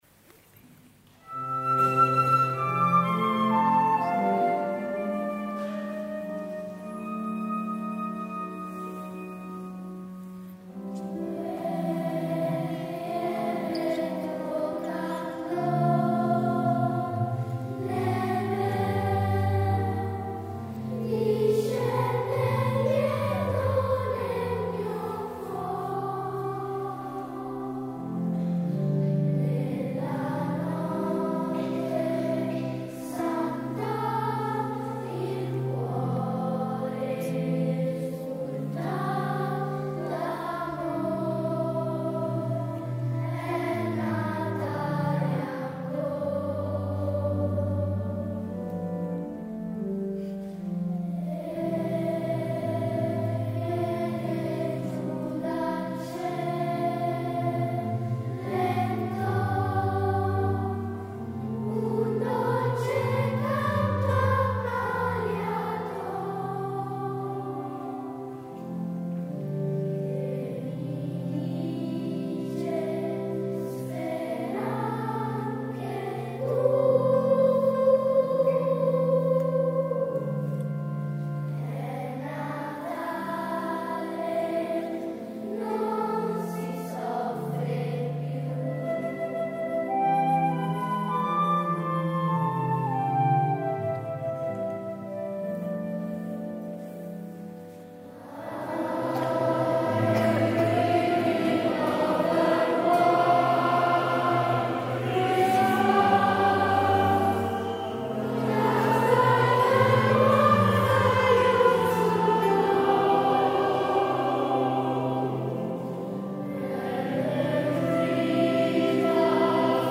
S. Gaudenzio church choir Gambolo' (PV) Italy
audio del concerto